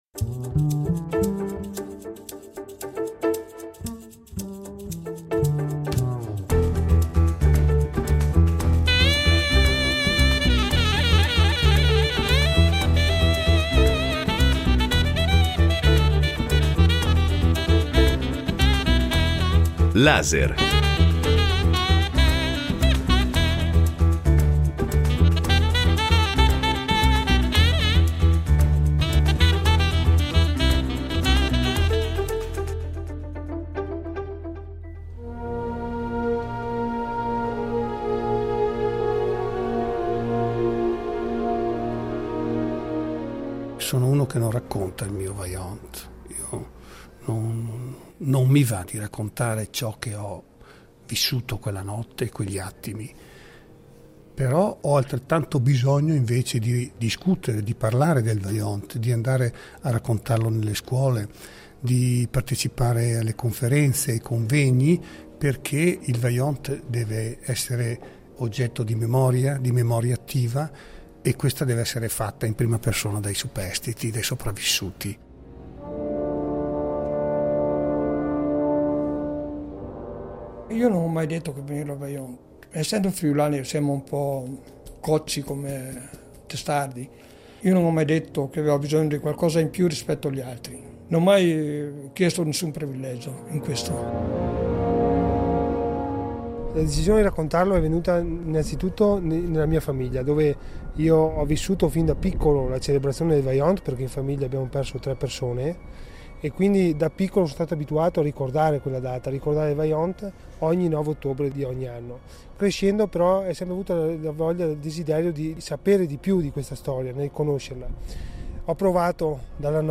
LASER ripropone il documentario realizzato in occasione del cinquantesimo anniversario.